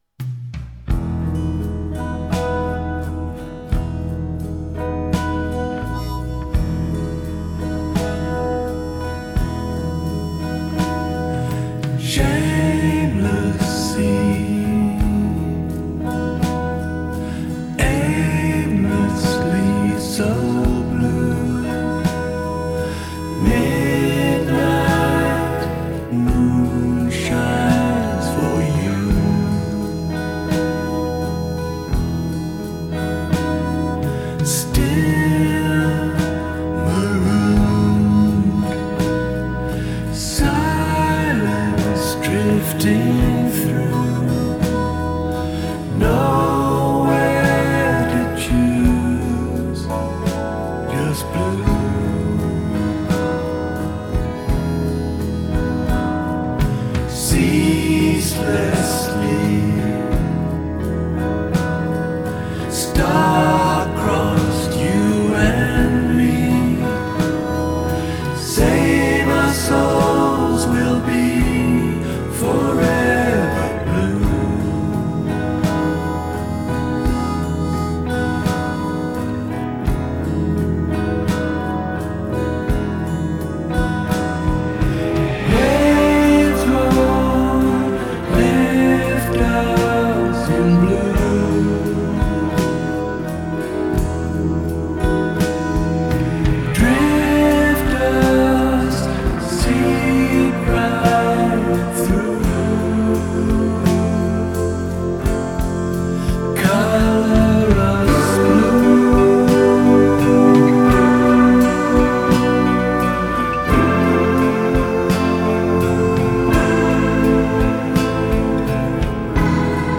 پراگرسیو راک آرت راک